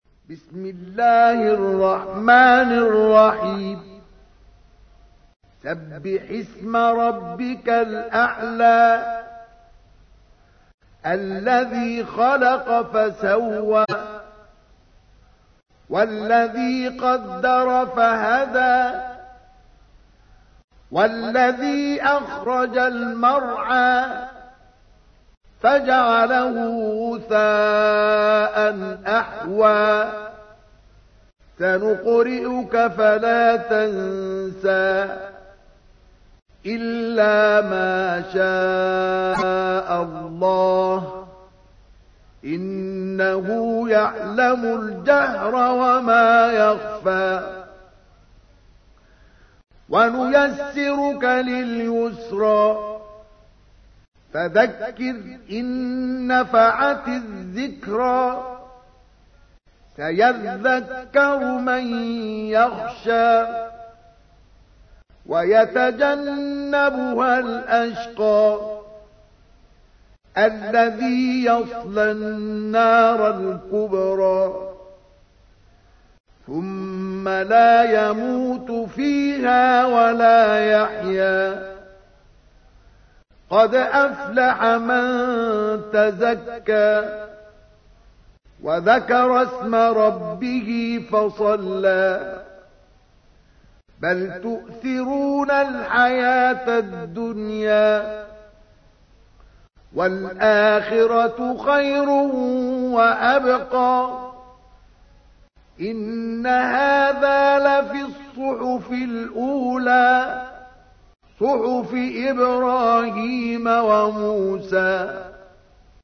تحميل : 87. سورة الأعلى / القارئ مصطفى اسماعيل / القرآن الكريم / موقع يا حسين